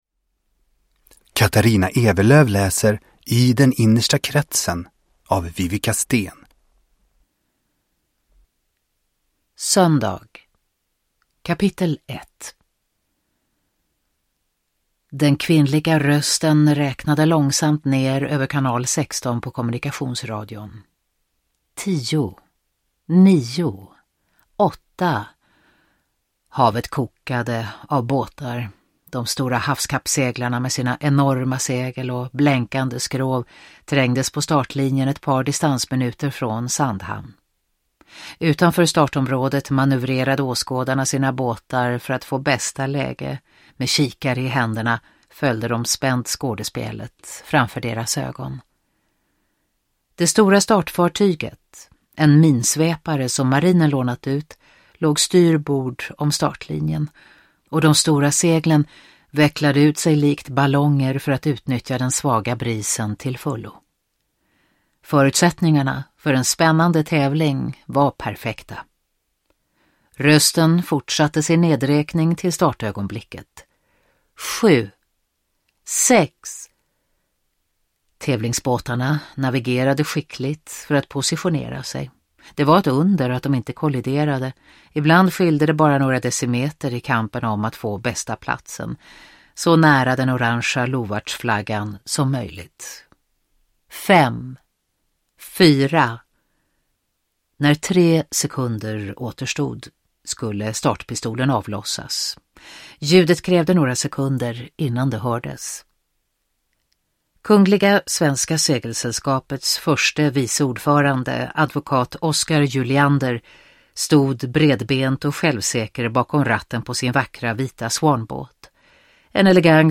Nedladdningsbar Ljudbok